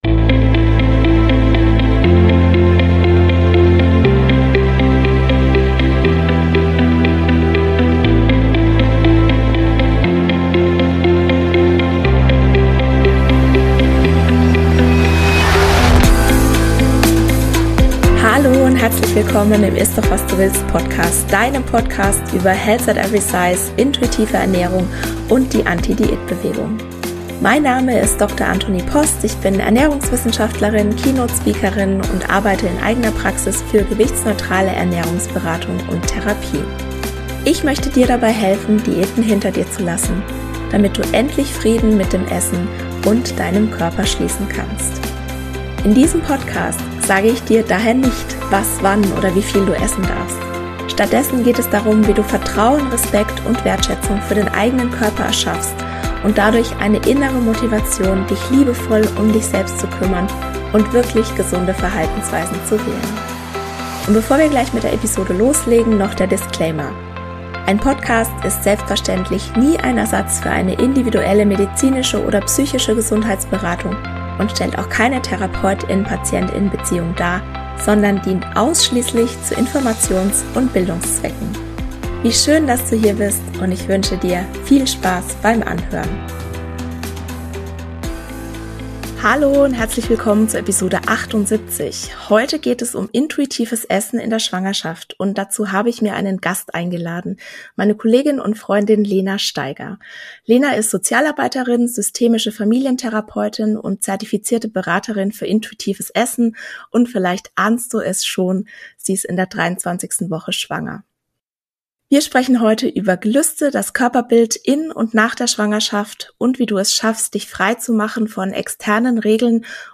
Interview-Special